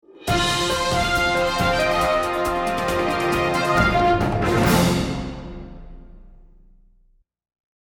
The entire spectrum of human emotions, each with its own musical snippet to set the stage. For instance, here’s one that is for when you have news you want to share: